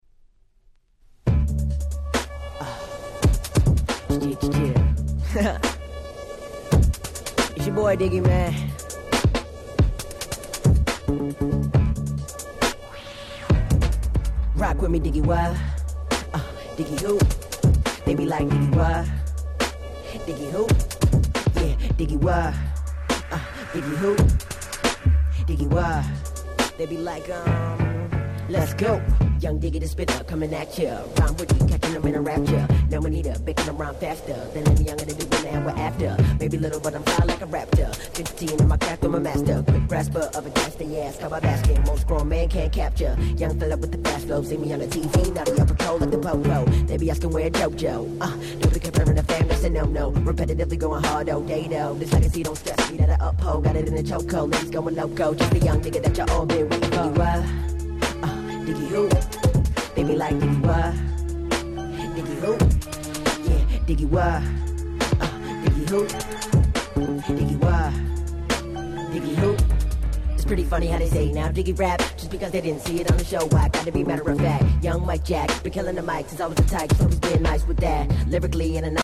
09' Nice Hip Hop !!